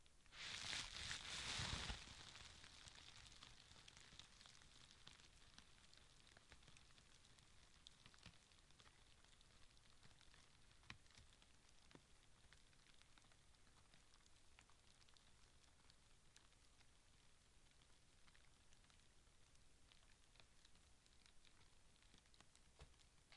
火焰噼啪作响02
描述：这只是一段小火的噼啪声。里面有一些不相干的声音（一些抽鼻子的声音，远处的汽车经过，轻微的脚步声，钥匙声，相机的声音，还有一些鸟的声音），我只是提供了完整的文件，所以你可以编辑和使用最适合你的部分。
标签： 噼啪 噼啪声
声道立体声